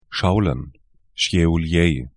Pronunciation
Schaulen 'ʃaulən Šiauliai ʃjɛŭ'ljɛĭ lt Stadt / town 55°56'N, 23°19'E